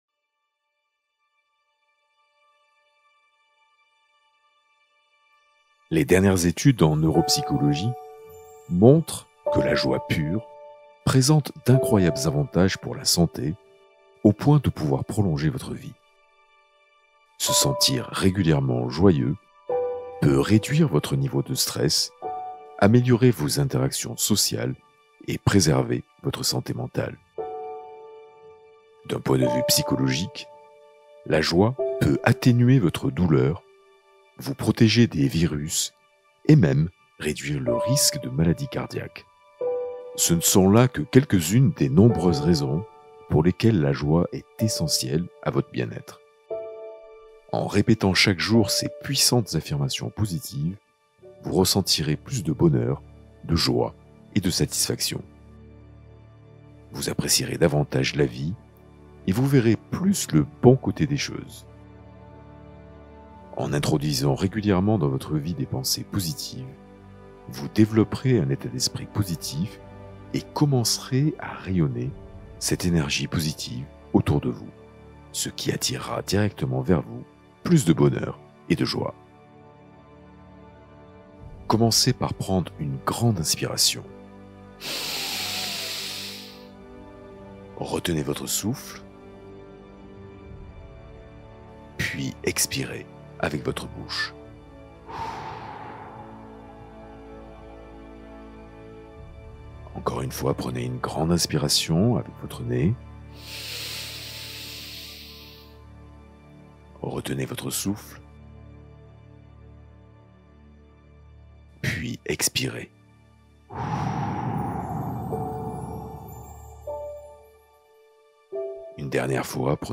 Hypnose pour clarifier ses intentions et les accompagner